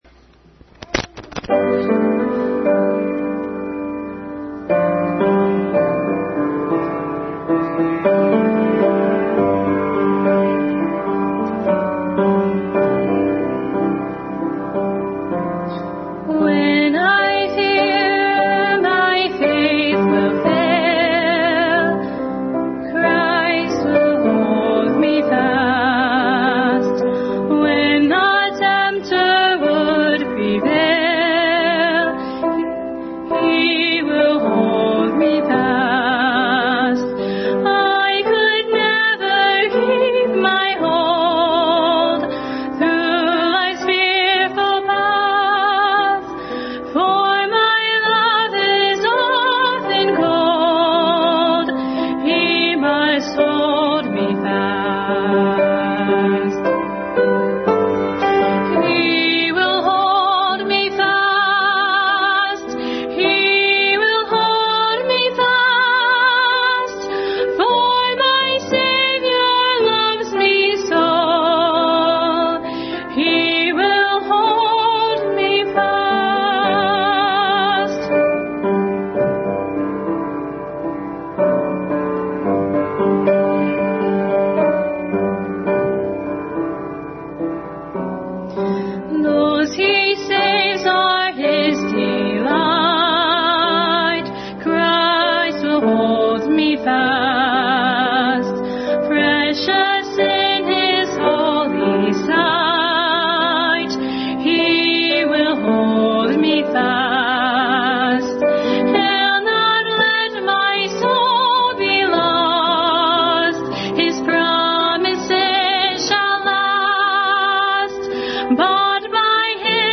Service Type: Family Bible Hour